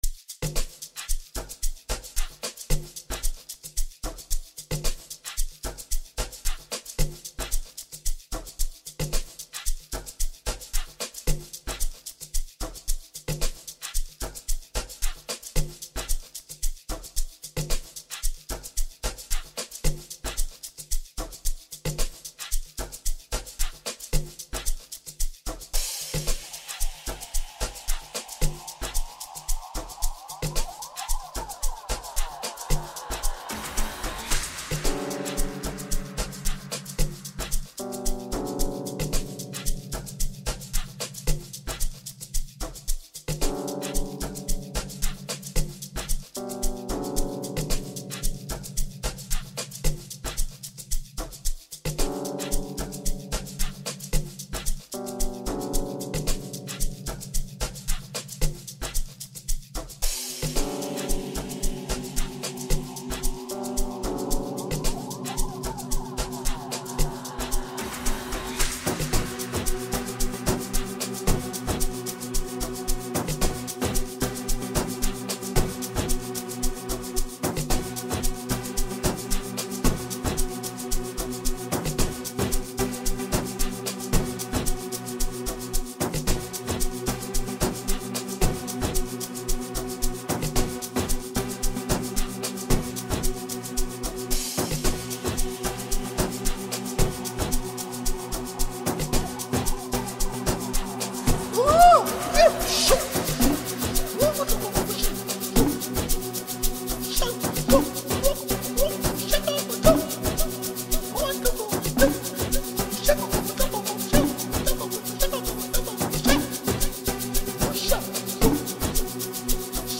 enchanting tune